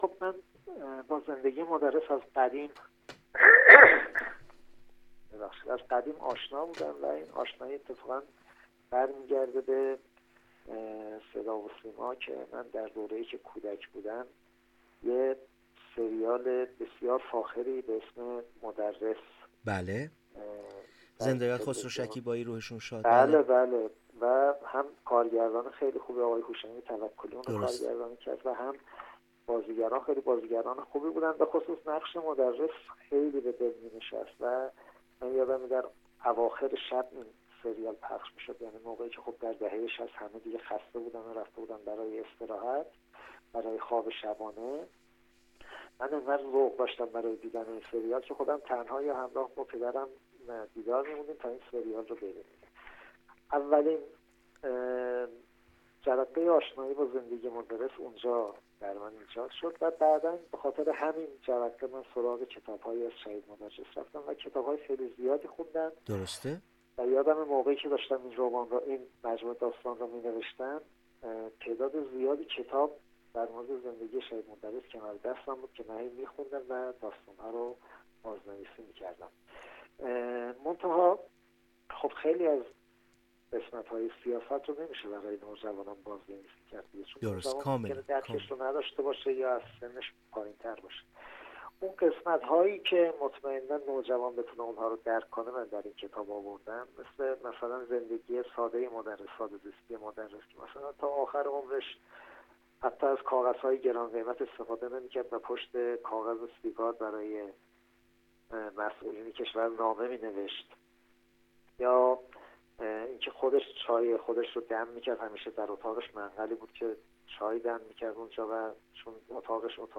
در چهارمین روز نمایشگاه کتاب تهران